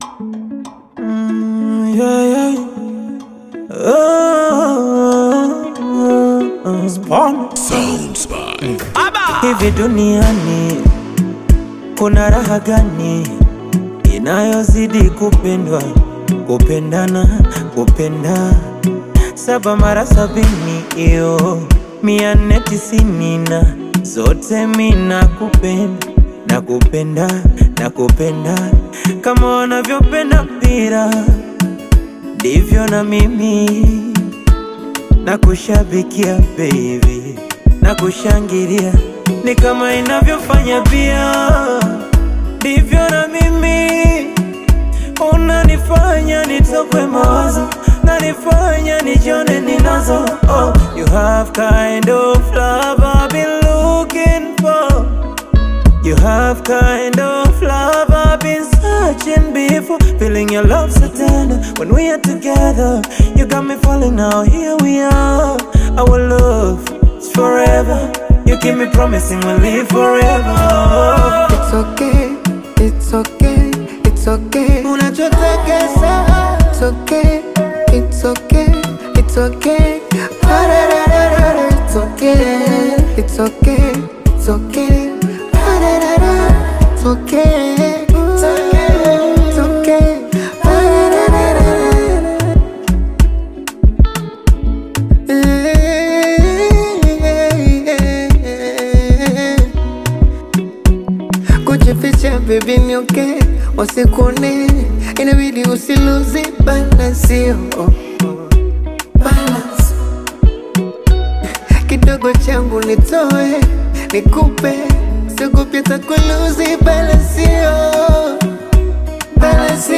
melodic Tanzanian Bongo Flava/Afro-Pop collaboration
smooth vocal delivery
soulful melodies
Bongo flava